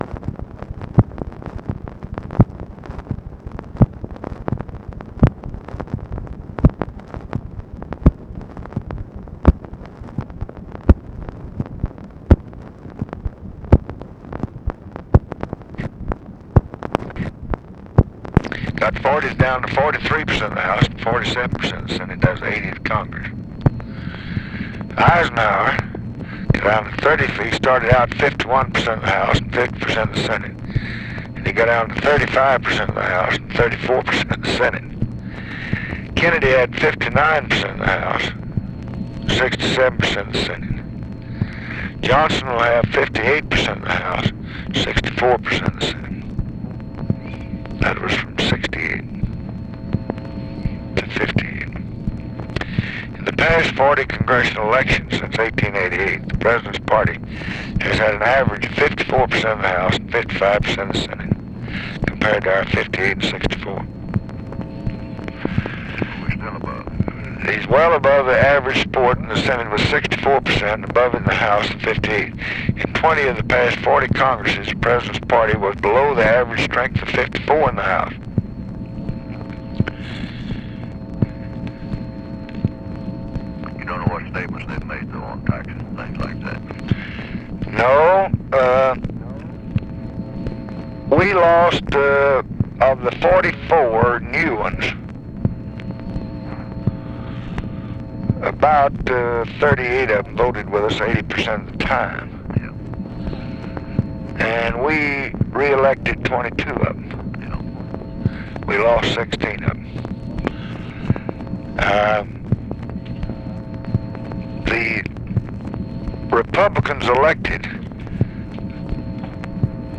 Conversation with WILBUR MILLS, November 12, 1966
Secret White House Tapes